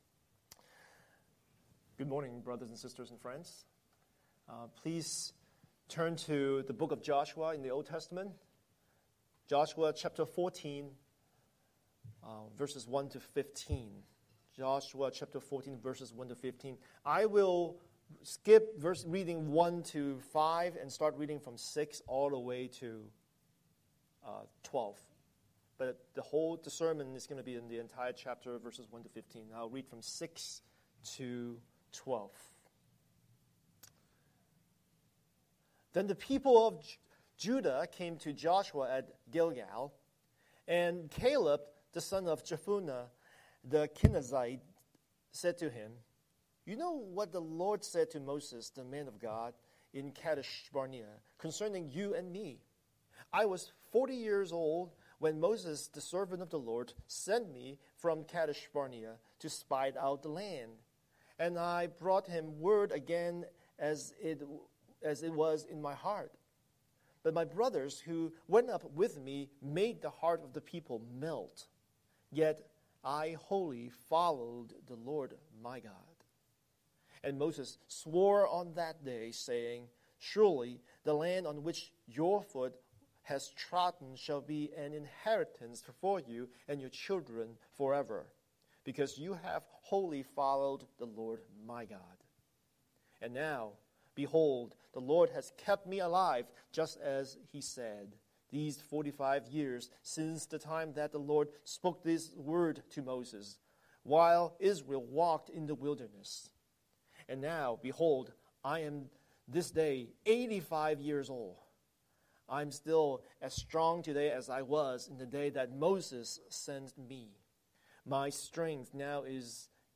Scripture: Joshua 14:1-15 Series: Sunday Sermon